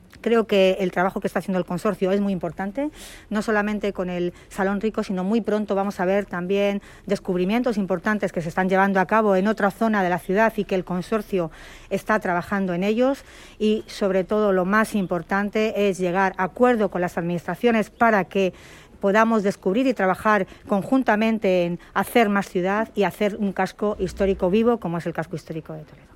AUDIOS. Milagros Tolón, alcaldesa de Toledo